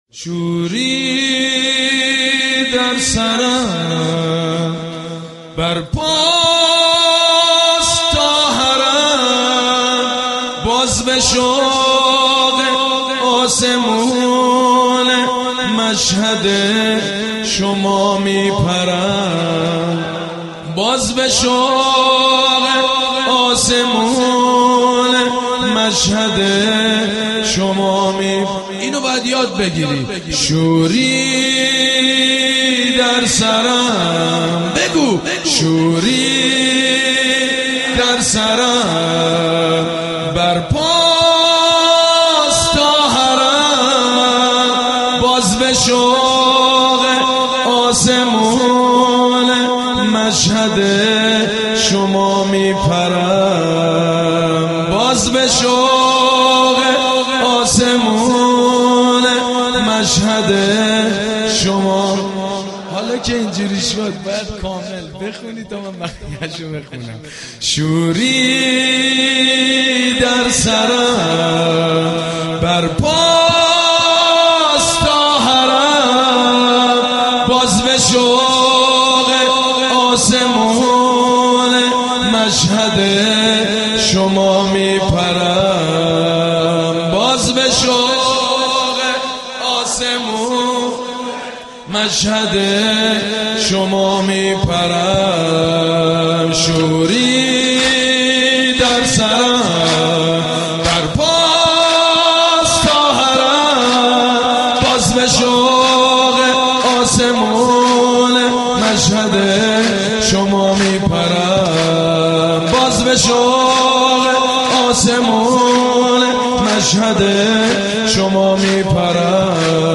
«میلاد امام رضا 1392» سرود: شوری در سرم، بر پاست تا حرم